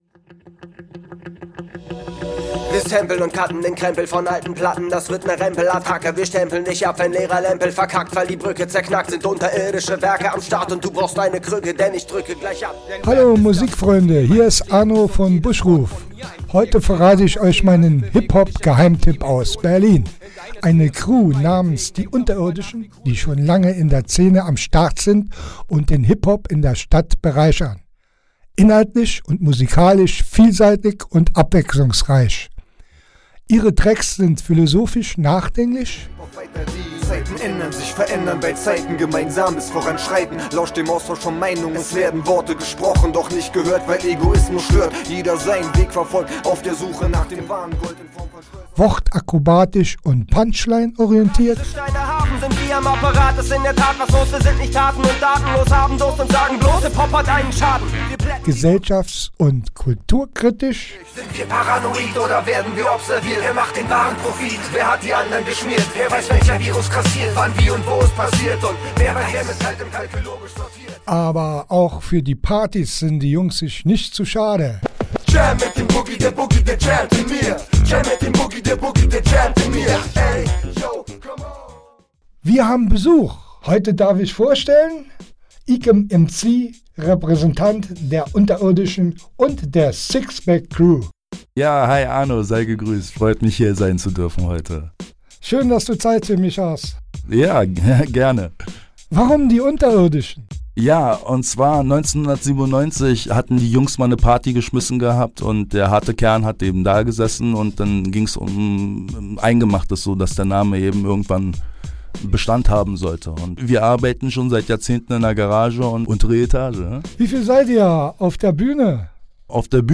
Musik
Diese Crew, die sich schon seit Kindheitstagen kennt, macht deutschsprachigen Rap von nachdenklich über wortakrobatisch bis partytauglich.